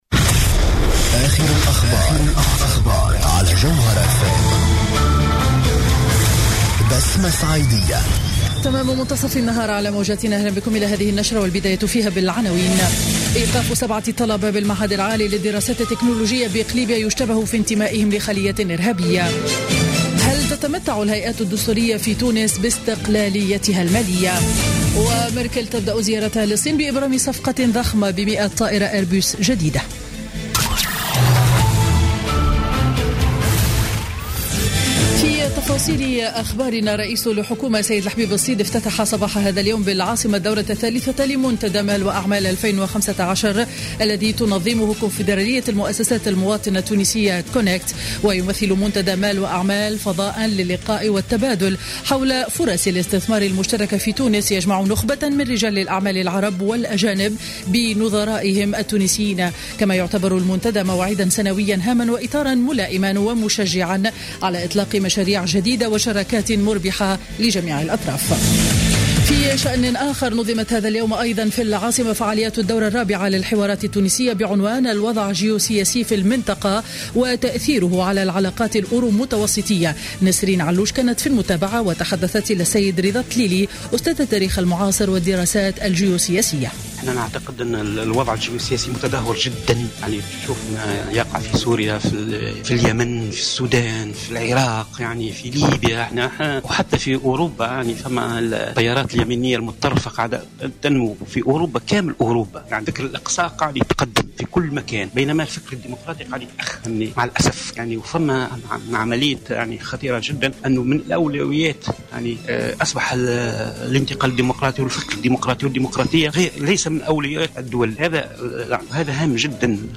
نشرة أخبار منتصف النهار ليوم الخميس 29 أكتوبر 2015